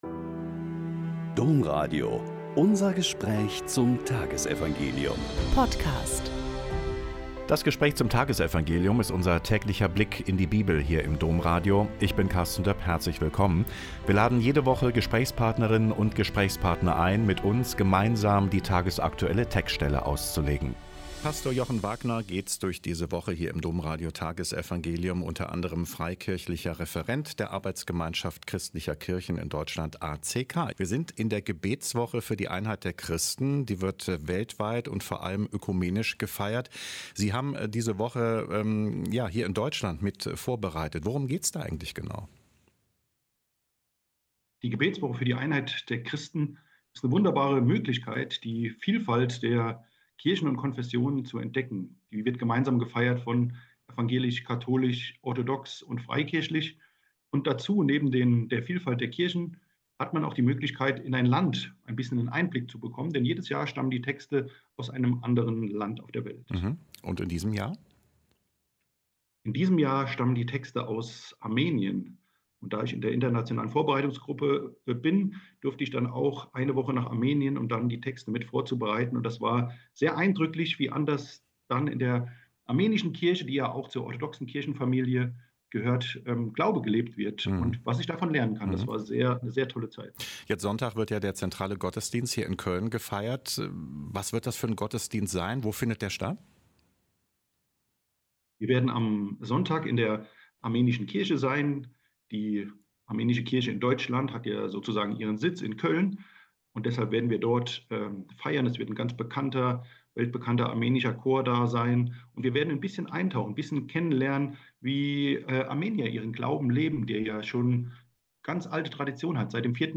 Mk 3,7-12 - Gespräch